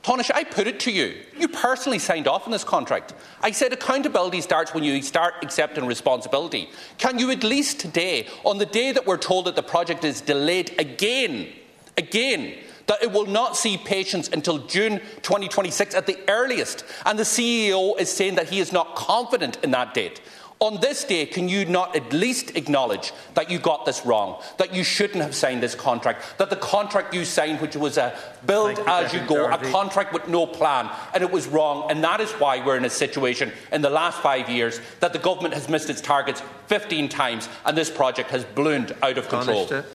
Sinn Fein Finance Spokesperson, Donegal Deputy Pearse Doherty called on Tánaiste Simon Harris to take accountability for the deal, as he was the Health Minister who signed off on it: